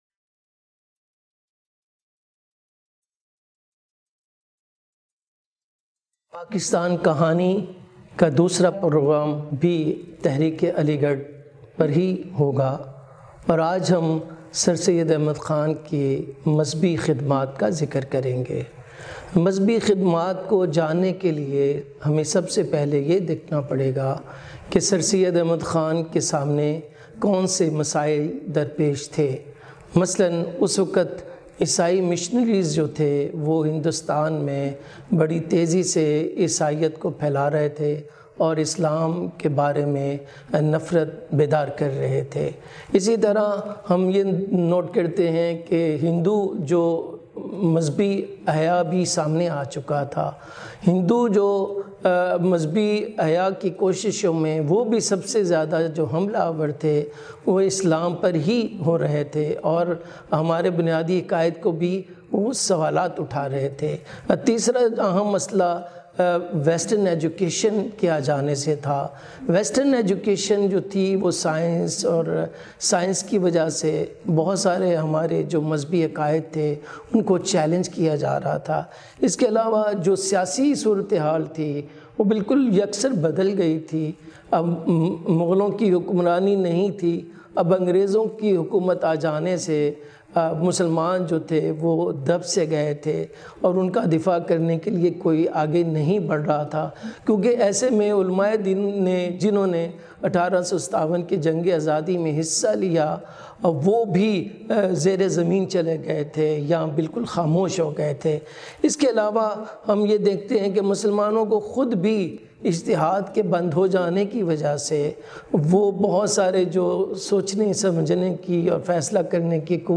Lecture # 02